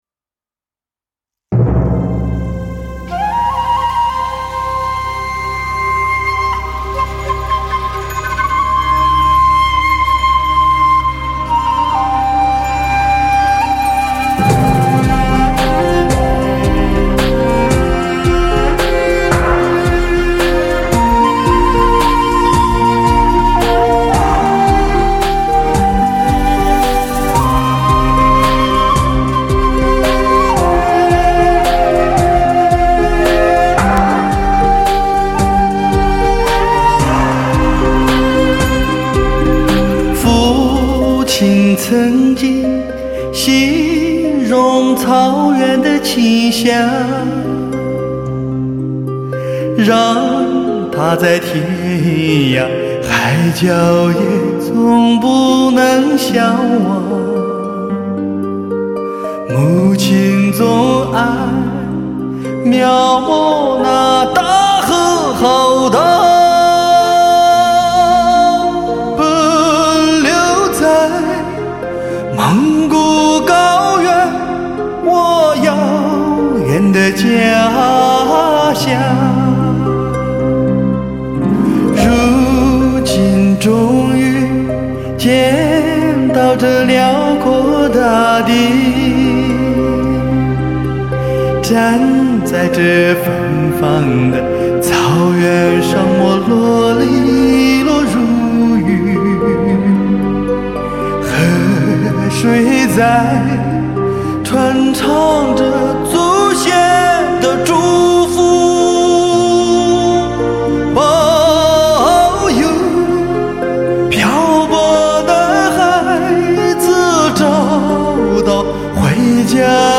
高清技术 高临场环绕音乐
使得音场更深、更宽、高频音频细腻伸延干净、通透
中频更圆润，低频下潜更深，让你置身中高临场音效之中